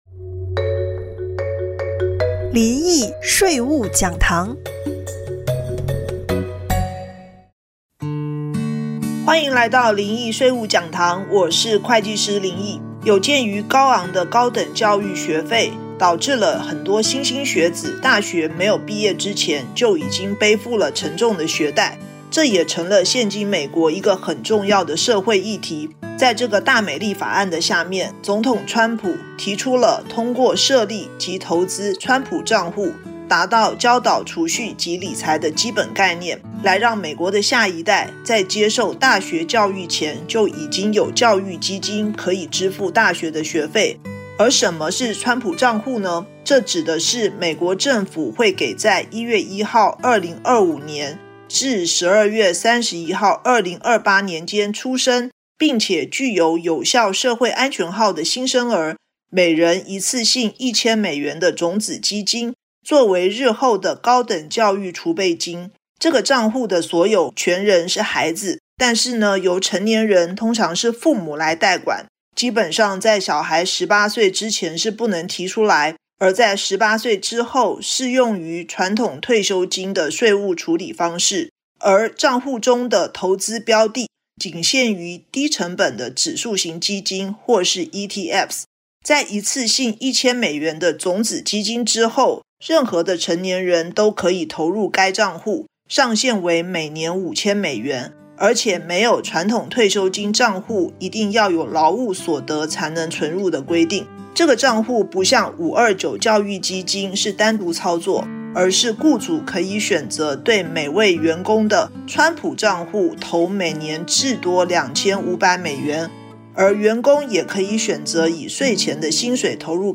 電台訪談